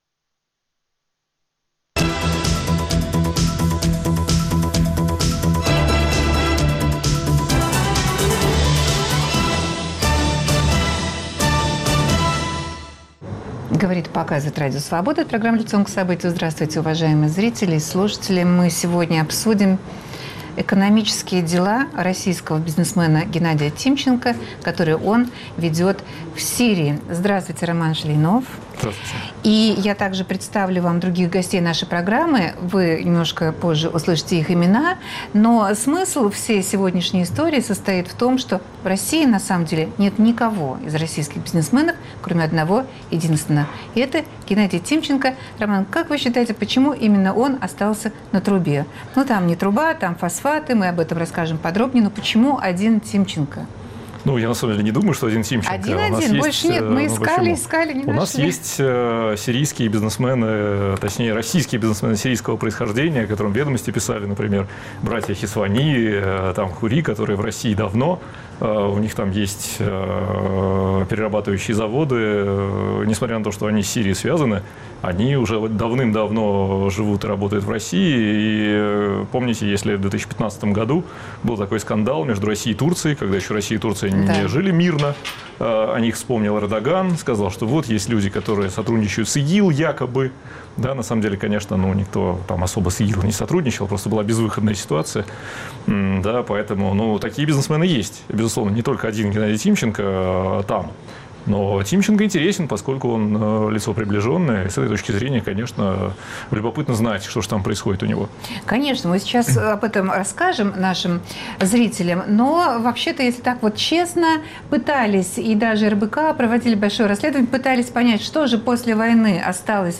Почему именно Тимченко командирован Путиным в Сирию на заработки? На какую сумму может обогатить его продажа сирийских фосфатов? Обсуждают журналисты-расследователи